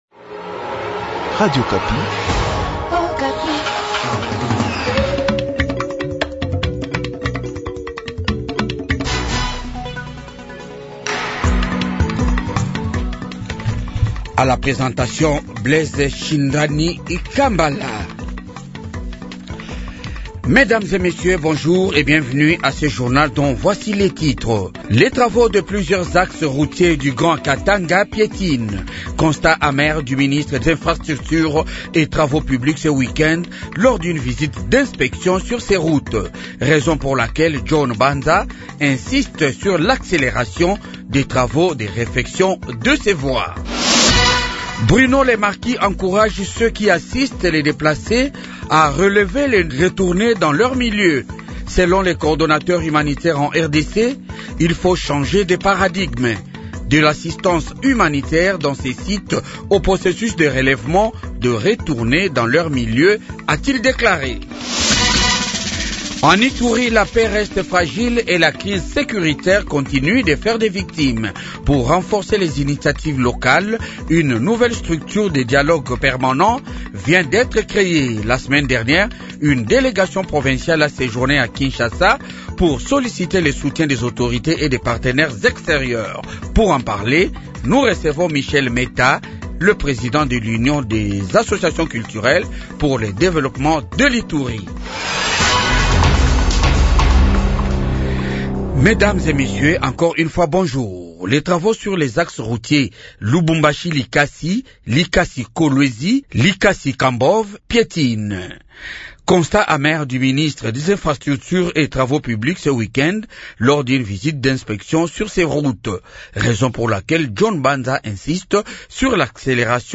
Journal Francais matin 8H